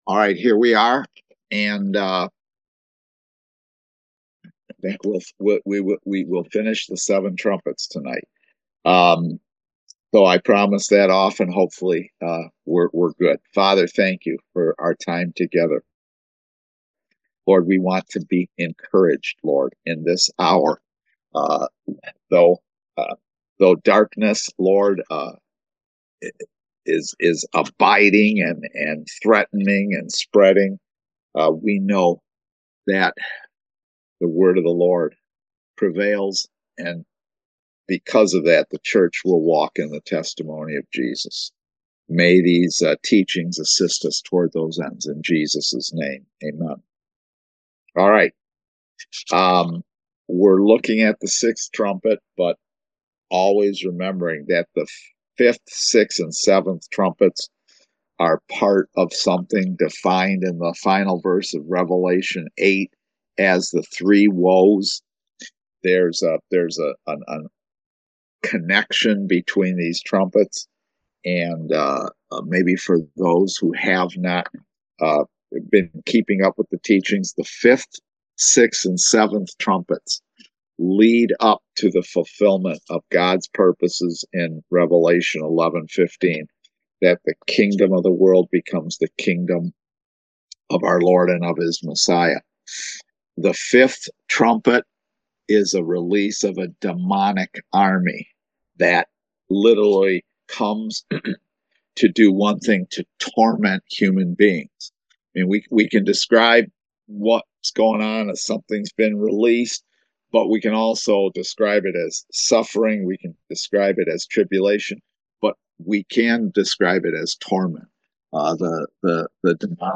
Service Type: Kingdom Education Class